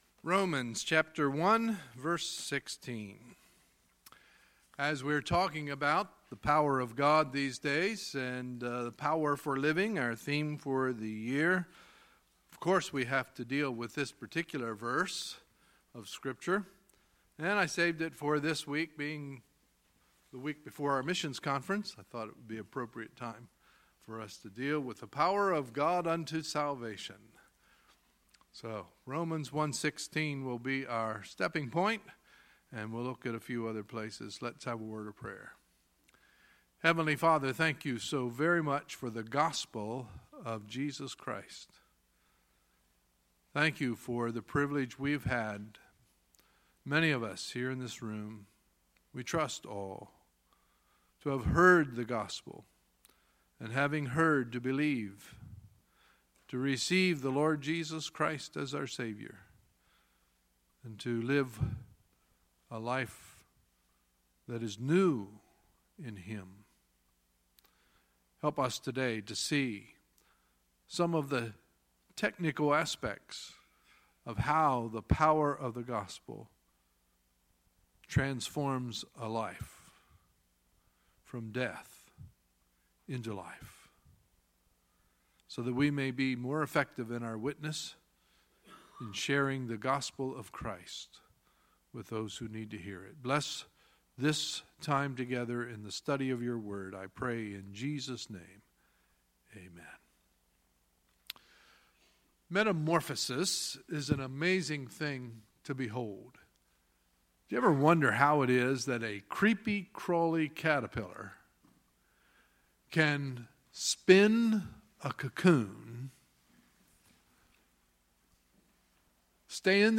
Sunday, September 17, 2017 – Sunday Morning Service